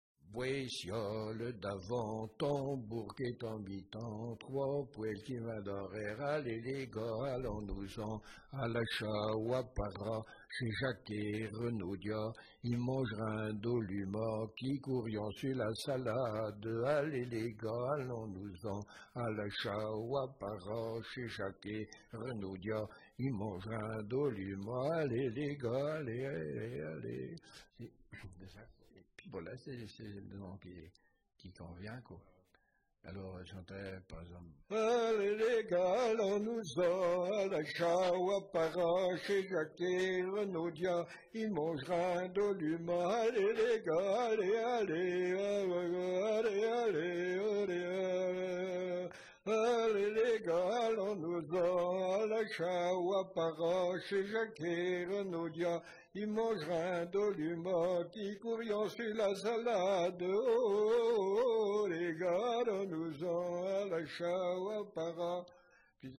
Pibolage ou appel de labour
refrains de dariolage
Appels de labour, tiaulements, dariolage, teurlodage, pibolage
couplets vocalisés